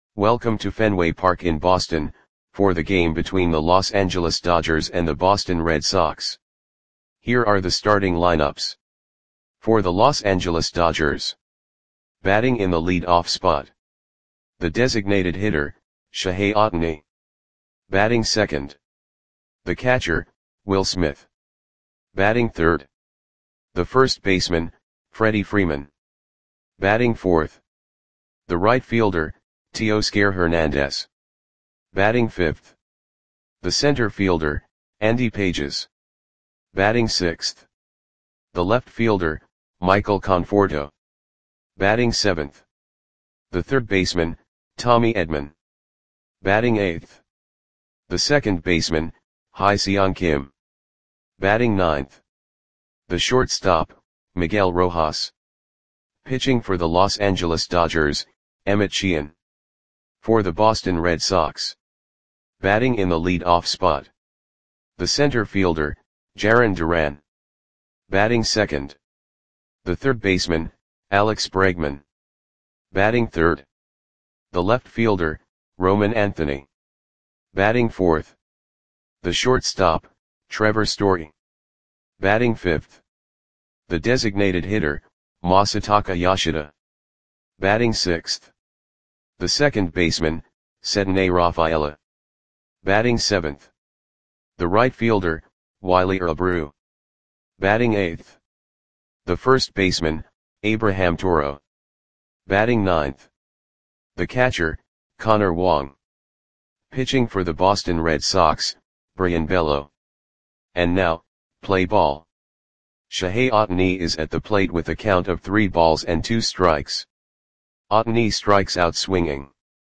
Audio Play-by-Play for Boston Red Sox on July 25, 2025
Click the button below to listen to the audio play-by-play.